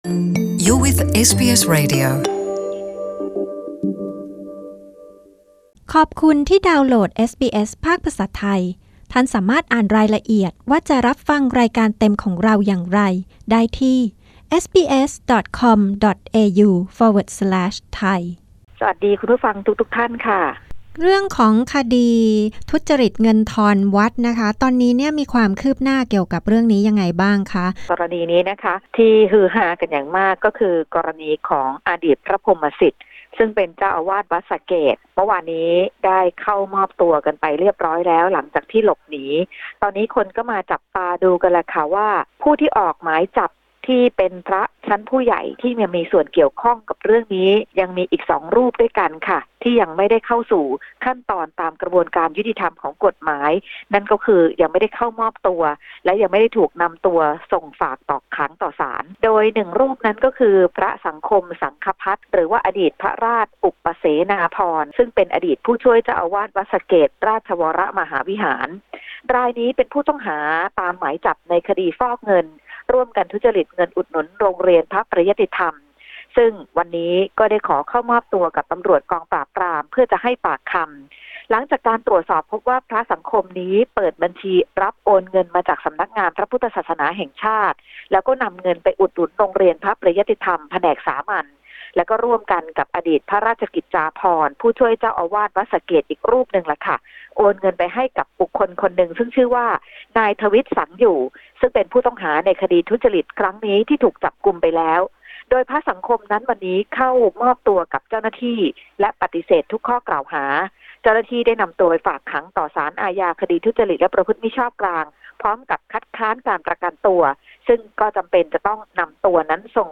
Thai phone-in news 31 MAY 2018